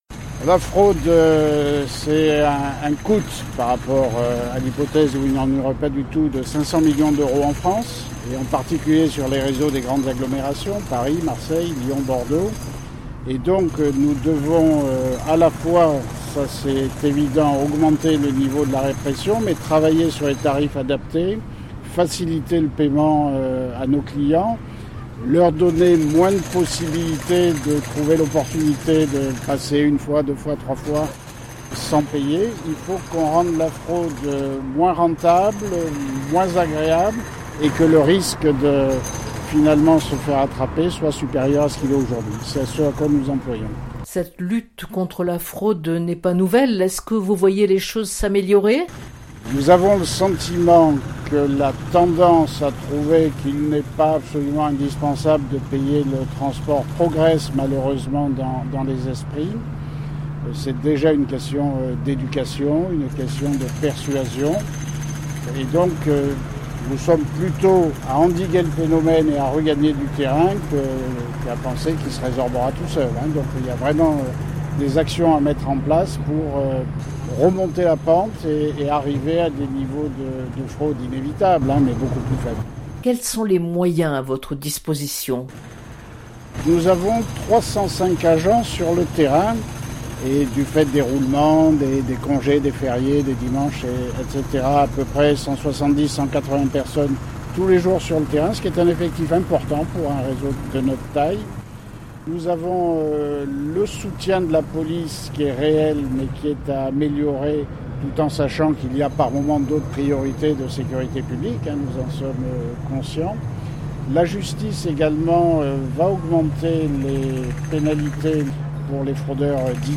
au son des marteaux-piqueurs, les moyens mis en place pour lutter contre la fraude certes la répression mais également, un ensemble de mesures tarifaires…
Reportage